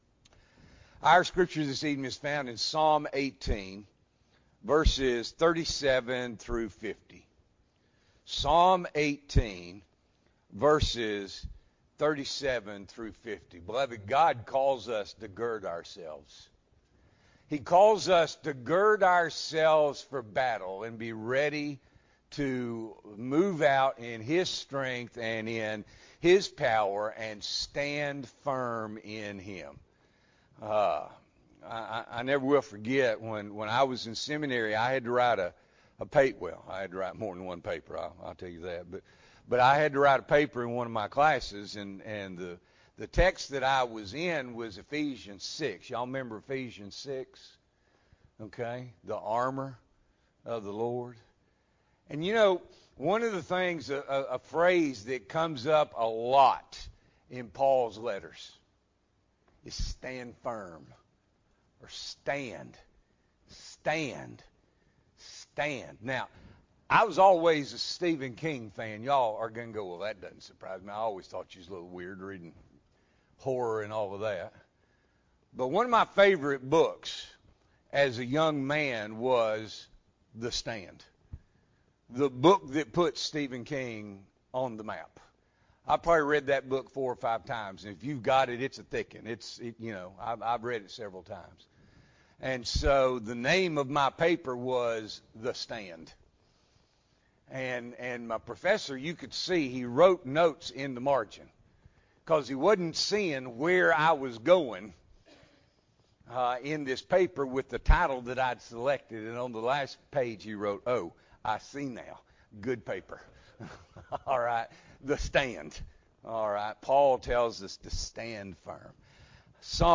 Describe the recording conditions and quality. April 16, 2023 – Evening Worship